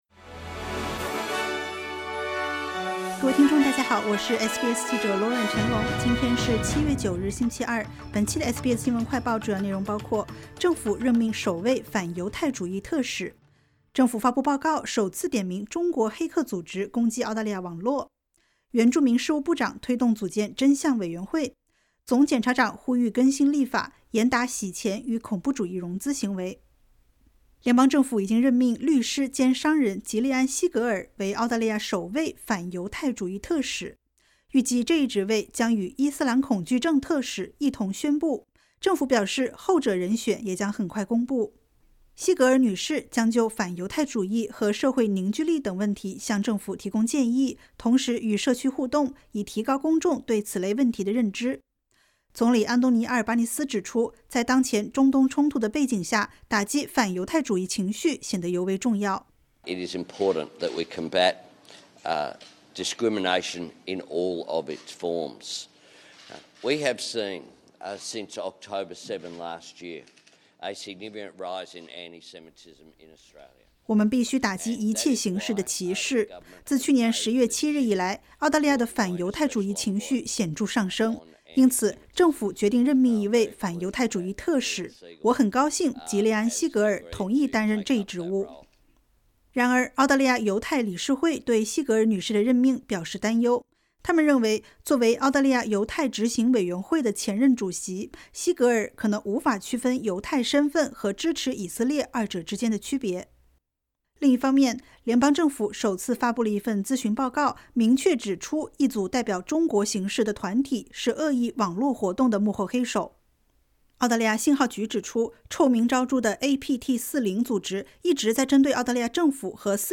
【SBS新闻快报】政府任命首位反犹太主义特使